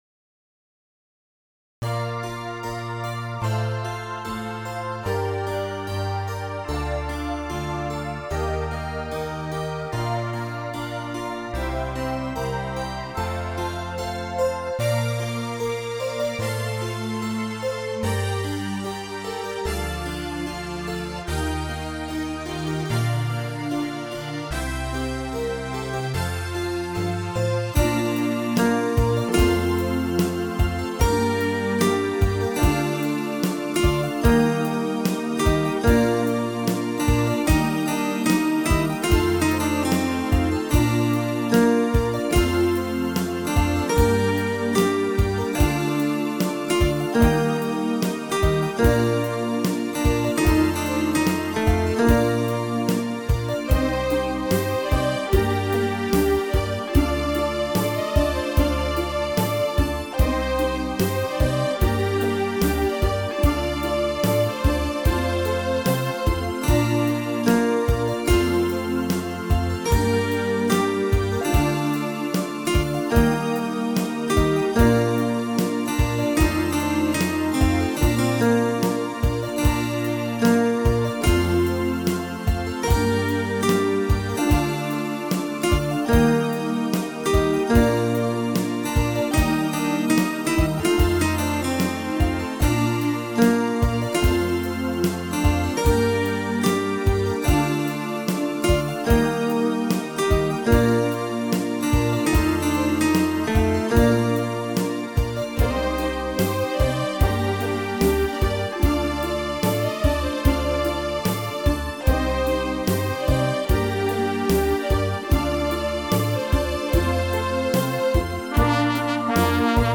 Voices  Zither1 + NewHeaven + AllegroStrings
GoldenTrumpet + HeavenPap + WarmPopHorns